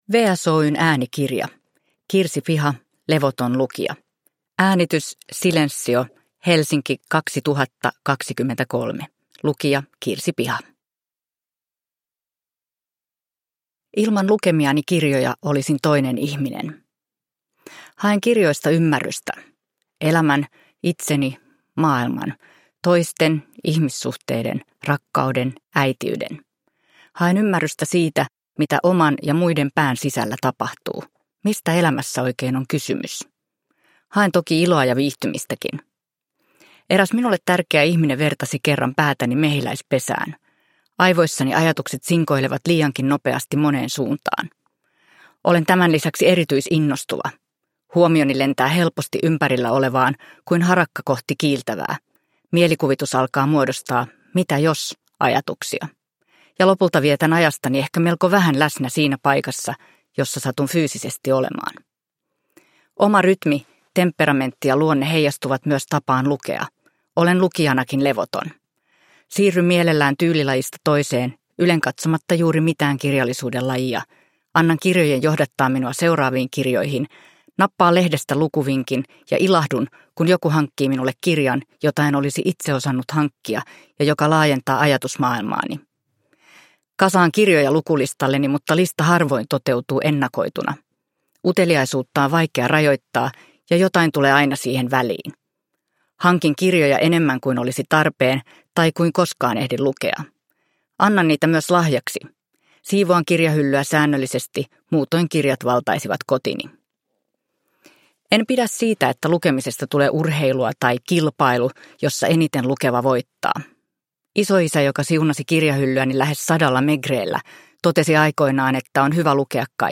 Levoton lukija – Ljudbok – Laddas ner